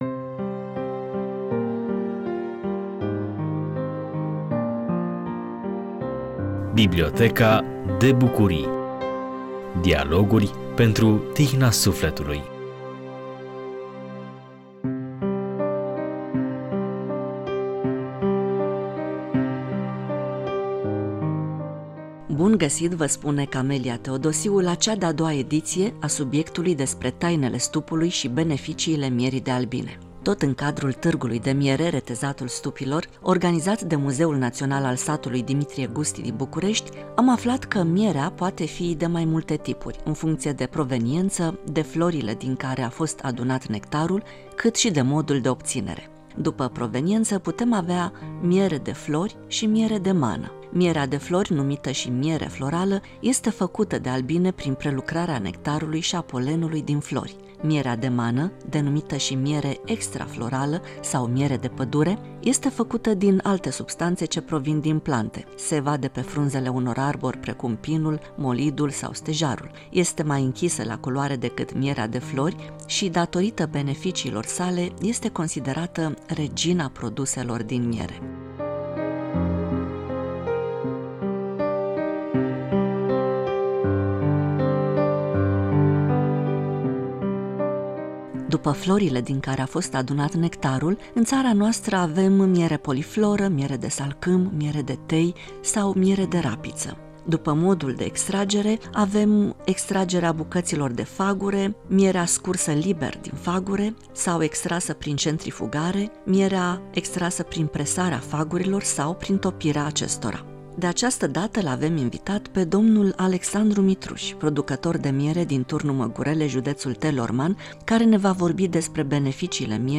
Acest dialog a fost realizat, ca și cel din ediția trecută, tot în cadrul Târgului de miere – Retezatul stupilor organizat de Muzeul Național al Satului “Dimitrie Gusti”.